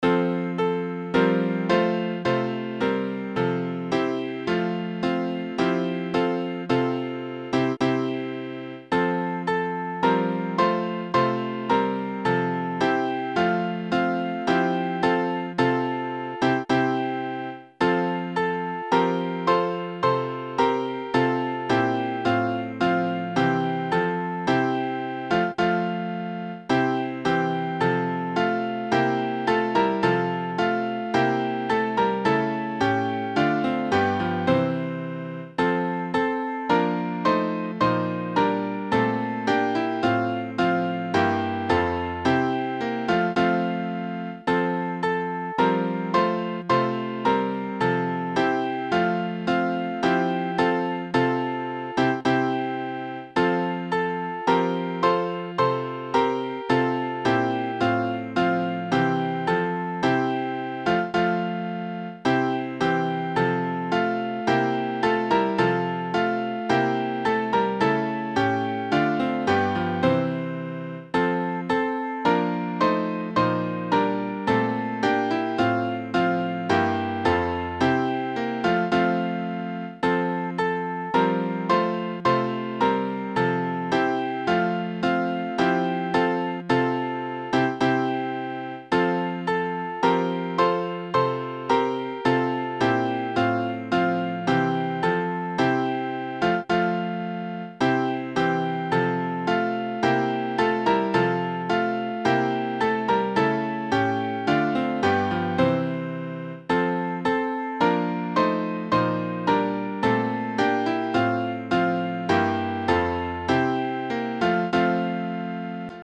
Midi File Transcriptions